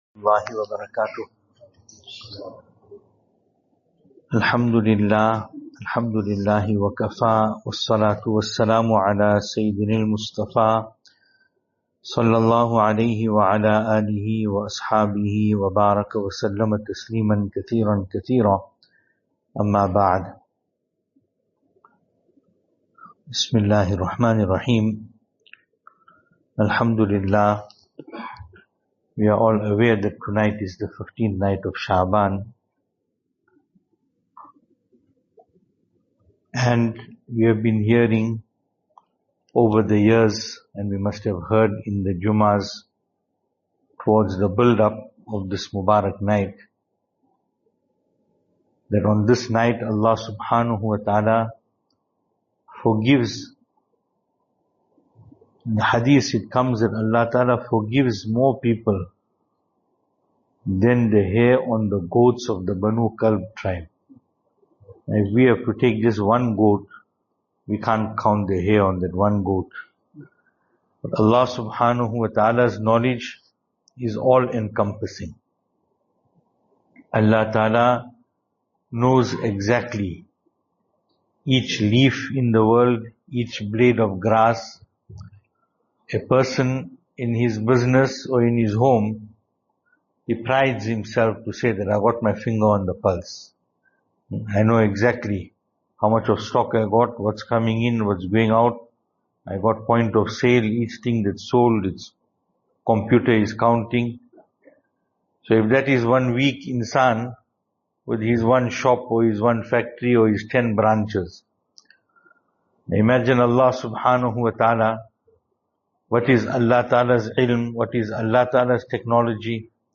15 Sha’baan Program. Talk, Zikr and Dua.